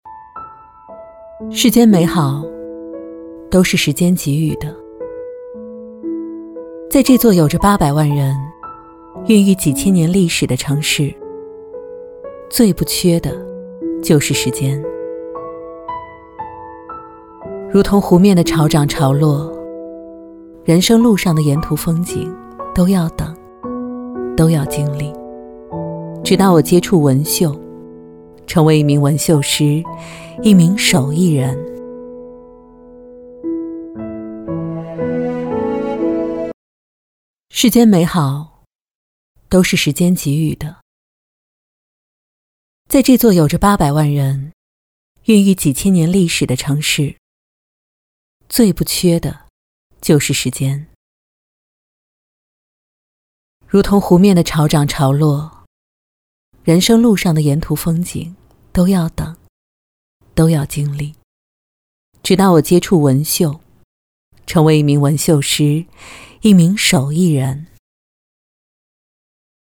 女国84_专题_人物_人物介绍_成熟.mp3